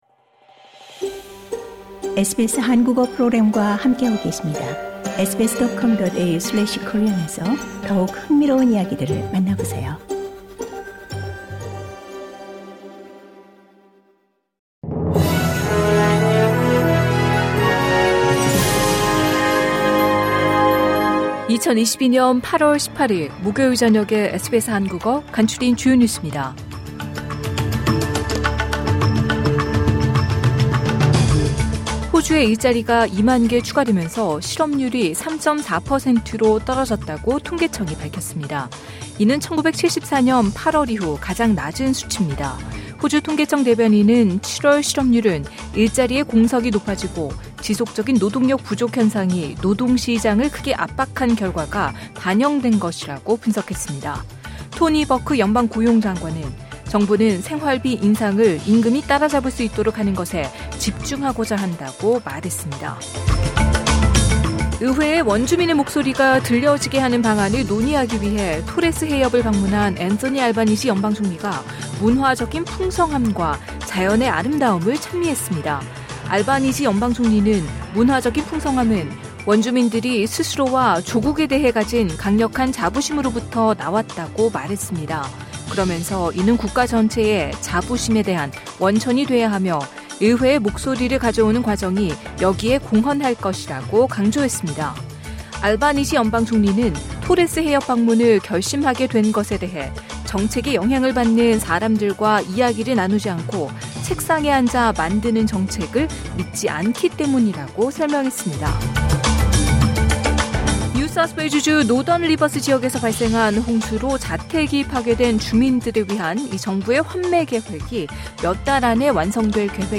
2022년 8월 18일 목요일 저녁 SBS 한국어 간추린 주요 뉴스입니다.